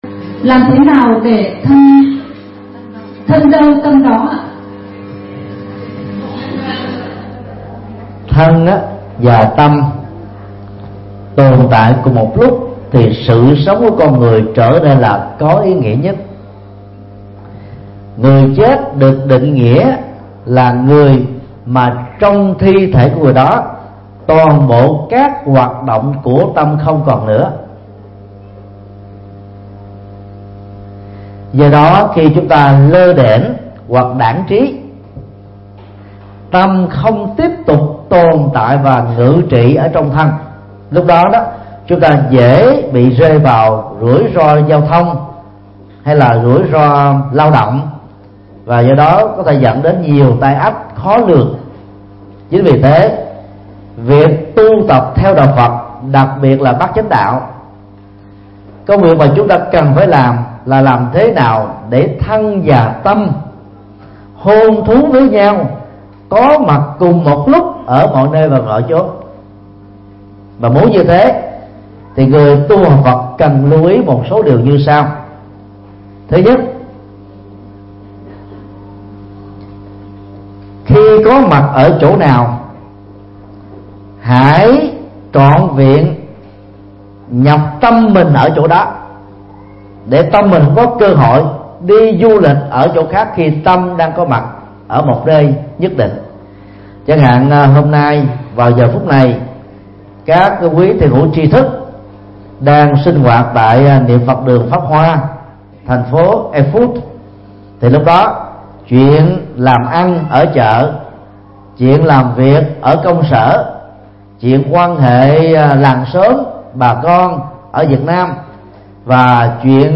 Vấn đáp: Thân đâu tâm đó – Mp3 Thầy Thích Nhật Từ Thuyết Giảng